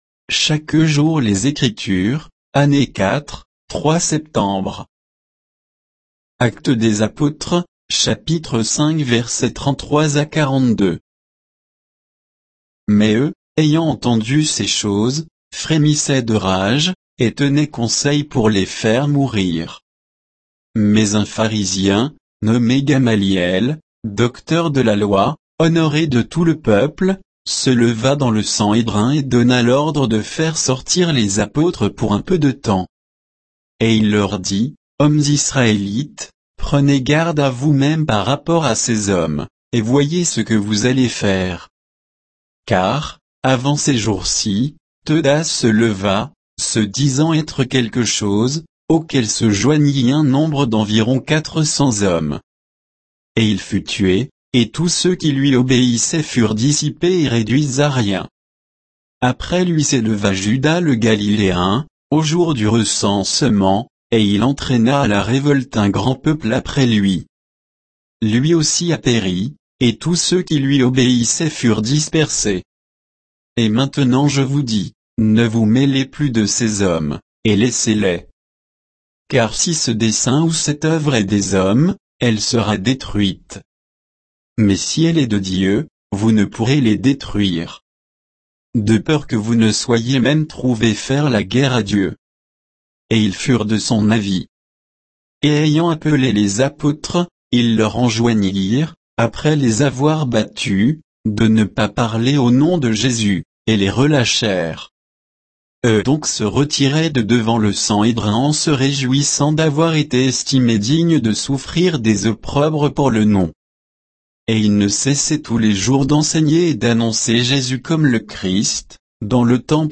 Méditation quoditienne de Chaque jour les Écritures sur Actes 5, 33 à 42